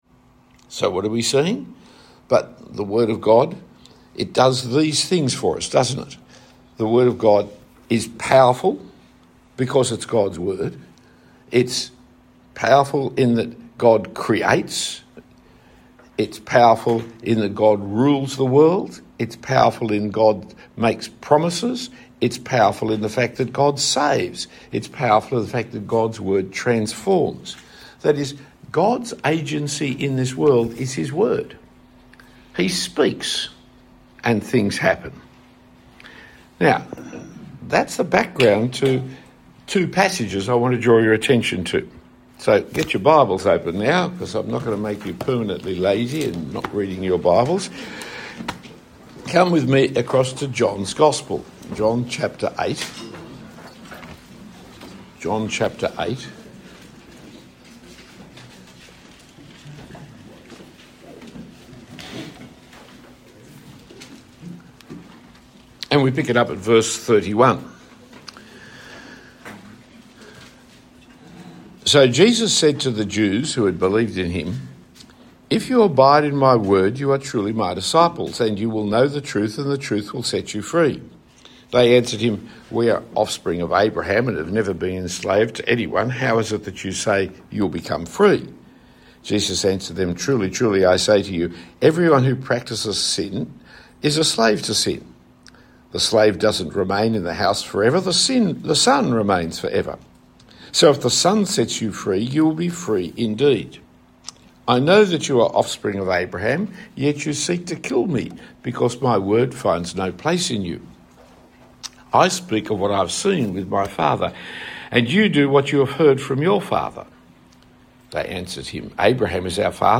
A talk given at the Launch leaders’ training day.